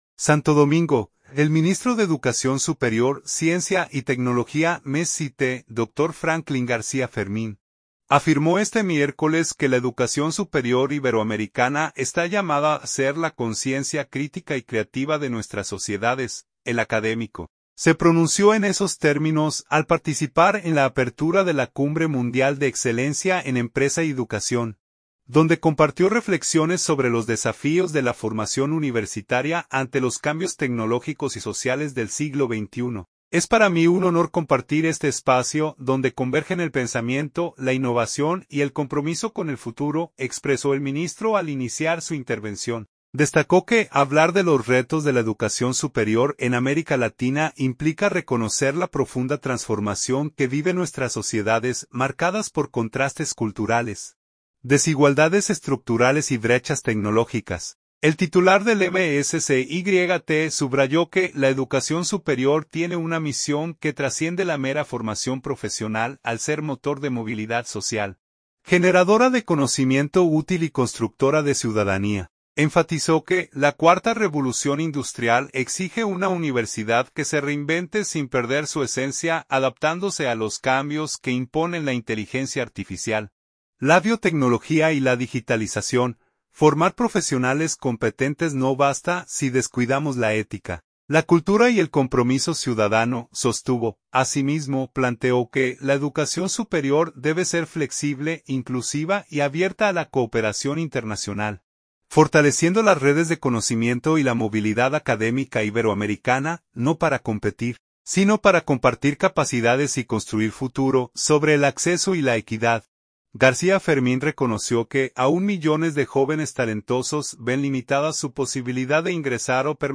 El académico, se pronunció en esos términos, al participar en la apertura de la Cumbre Mundial de Excelencia en Empresa y Educación, donde compartió reflexiones sobre los desafíos de la formación universitaria ante los cambios tecnológicos y sociales del siglo XXI.